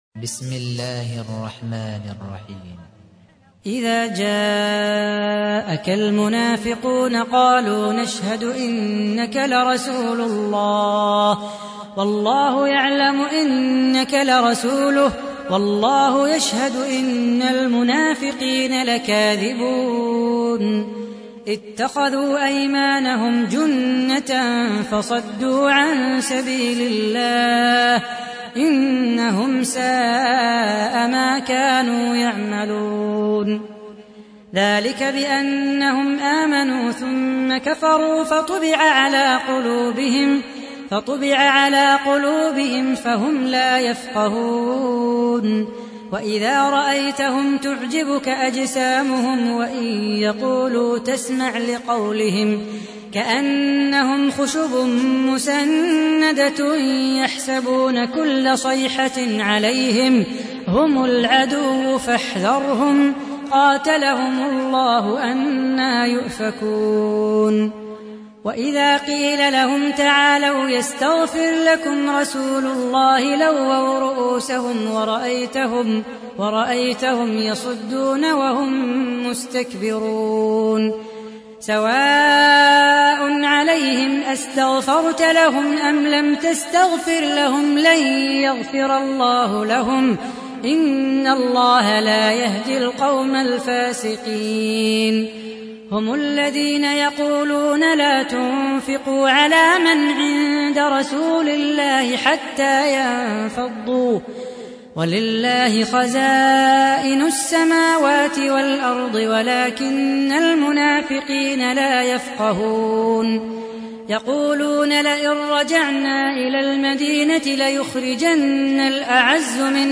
تحميل : 63. سورة المنافقون / القارئ صلاح بو خاطر / القرآن الكريم / موقع يا حسين